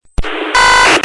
сигнал вызова у ВВ-шников
signal_vv.mp3